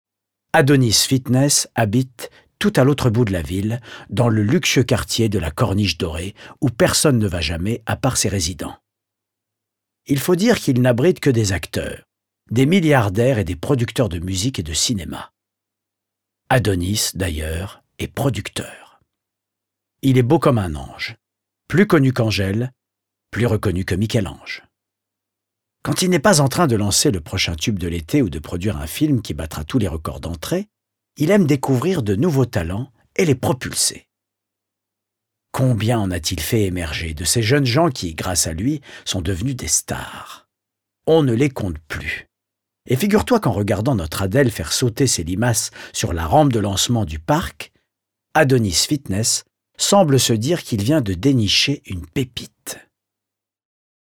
Livres Audio
« Suzanne Griotte et les métalimaces » de Bérard Thibault, lu par Laurent Stocker de La Comédie-Française